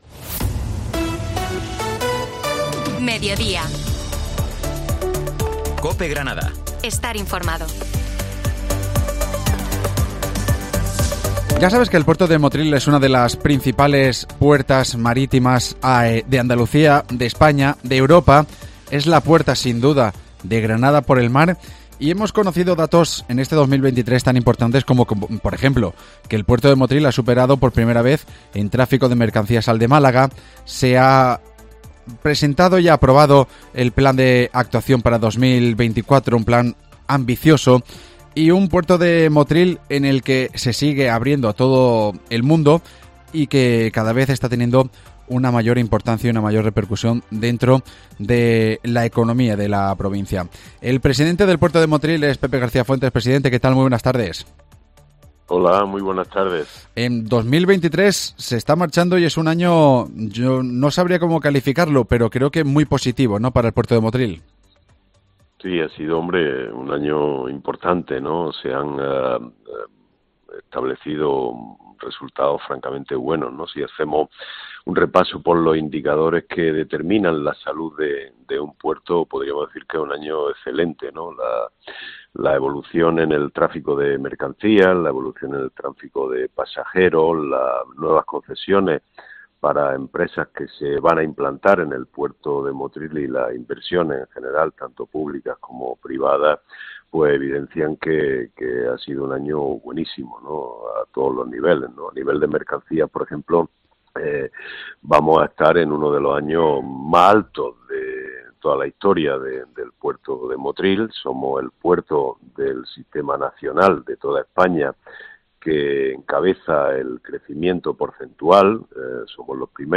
Hablamos con su presidente, Pepe García Fuentes, sobre el balance de este 2023 y los principales retos que tiene por delante la autoridad portuaria.